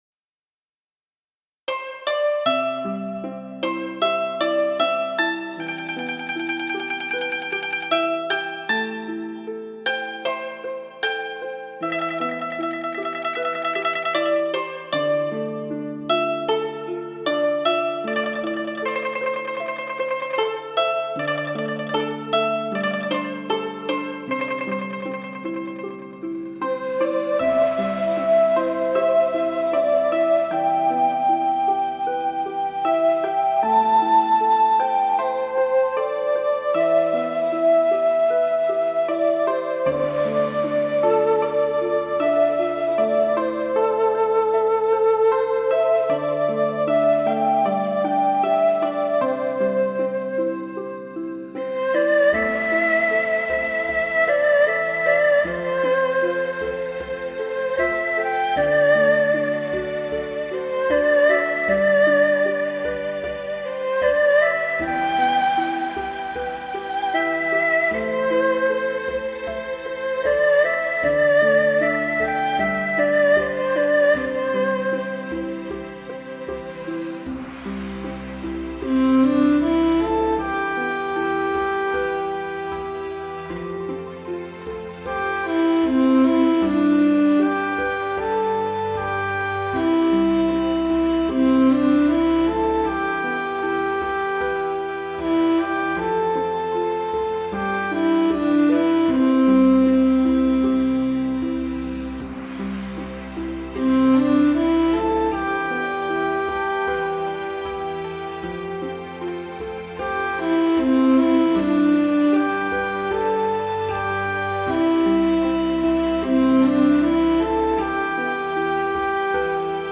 Beautiful Chinese Music